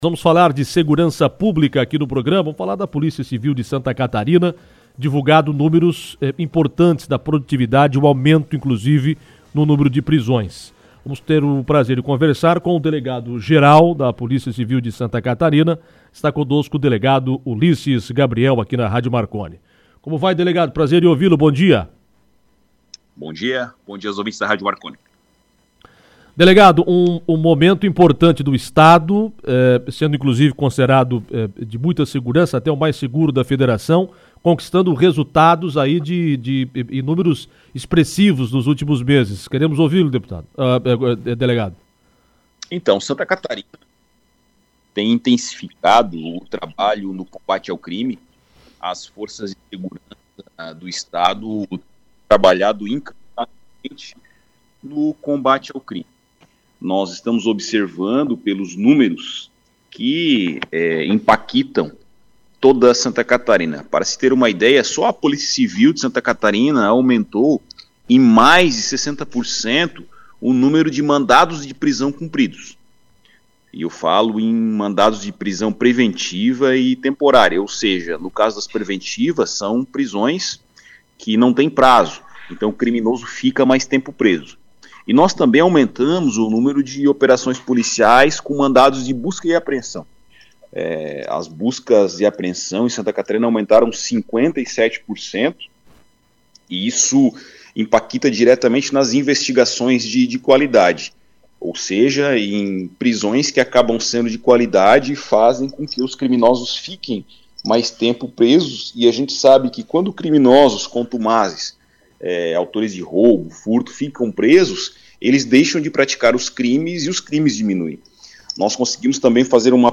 O assunto foi destaque em entrevista no programa Comando Marconi.